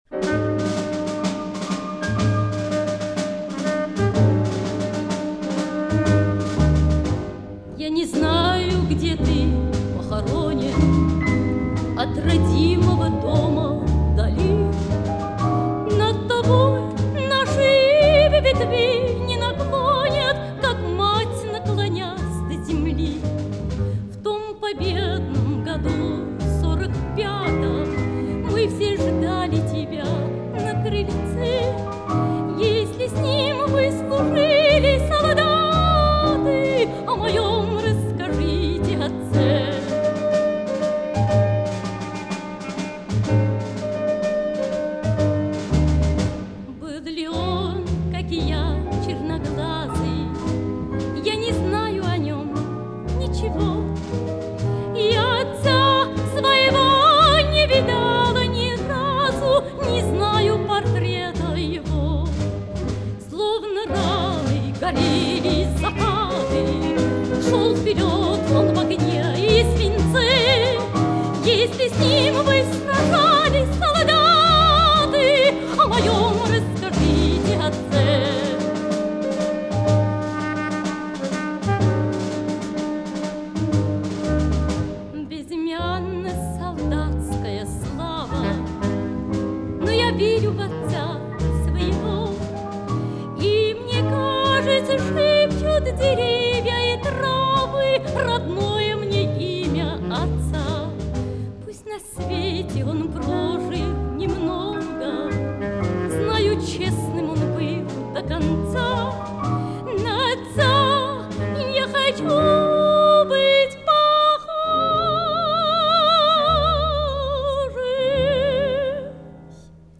Запись из домашнего архива